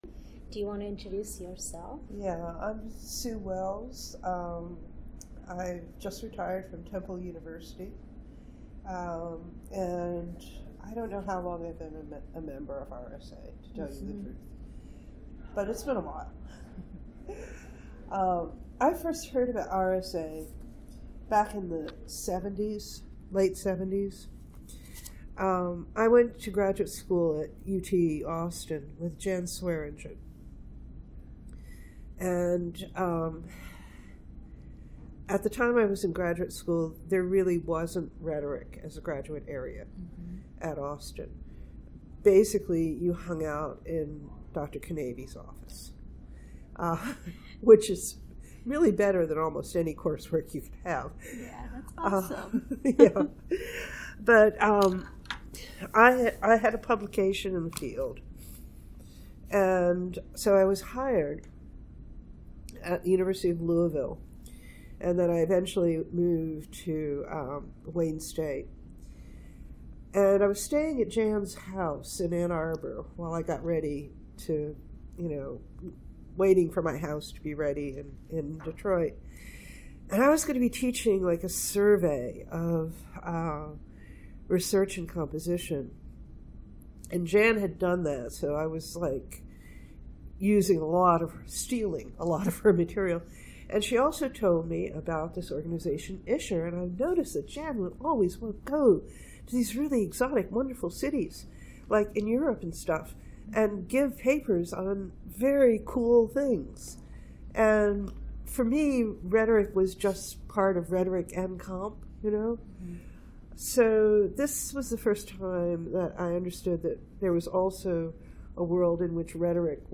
Oral History
Location 2018 RSA Conference in Minneapolis, Minnesota